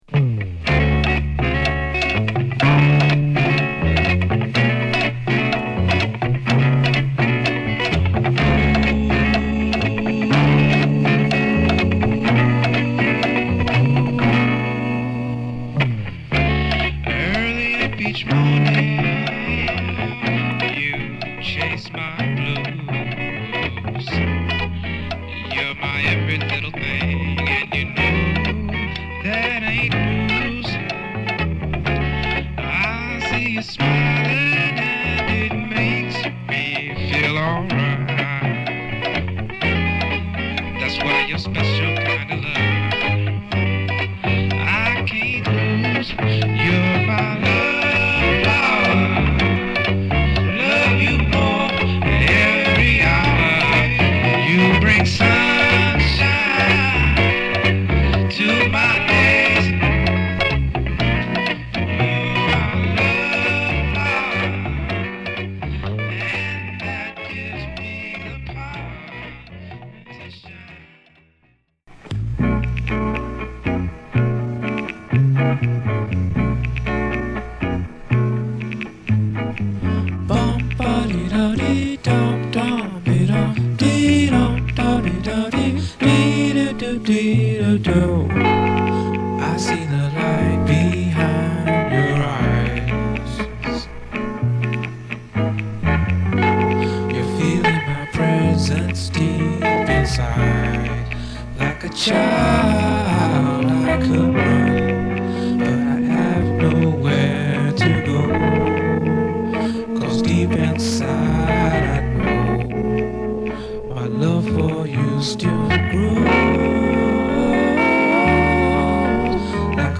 録音の悪さも手伝って独特の雰囲気を纏ってます。